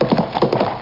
Hooves Sound Effect
Download a high-quality hooves sound effect.
hooves.mp3